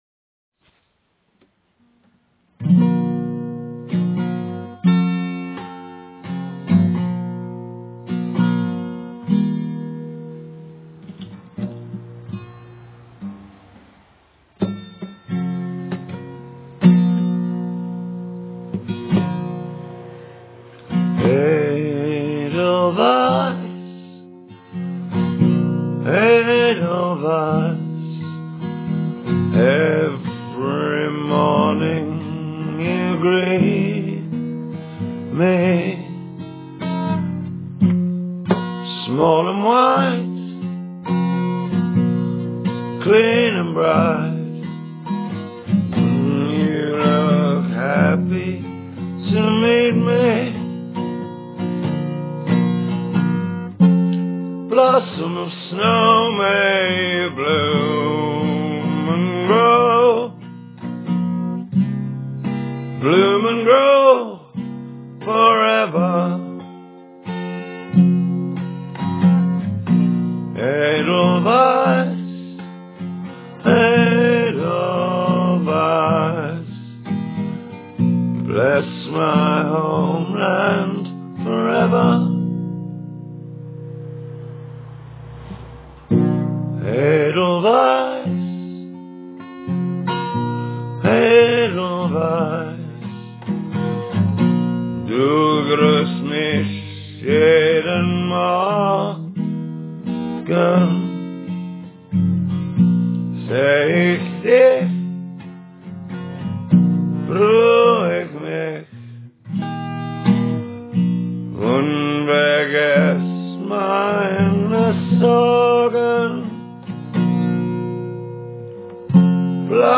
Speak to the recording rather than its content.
one take, got a cold